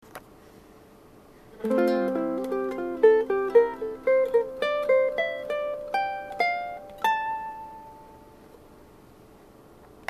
T's Guitars MTX-220 Mini Tenor コア Vブレーシングの初期ロッド